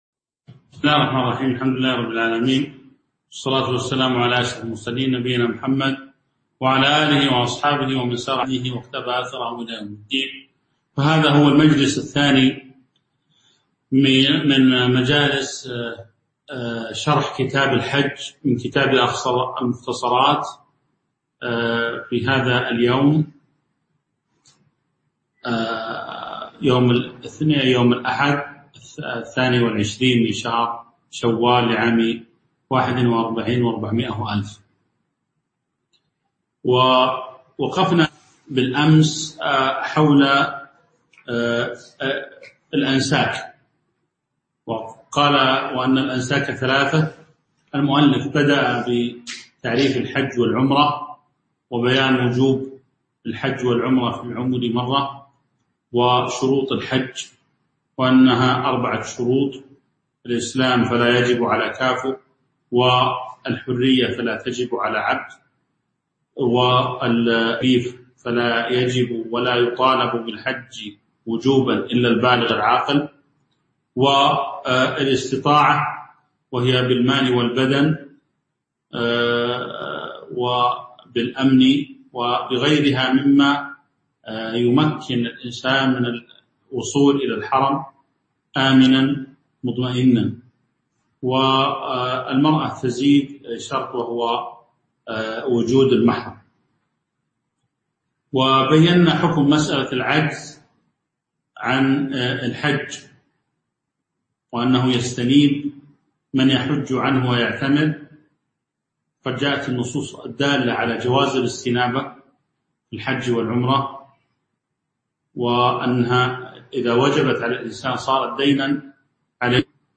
تاريخ النشر ٢٣ شوال ١٤٤١ هـ المكان: المسجد النبوي الشيخ